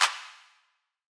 Claps
JJClap (13).wav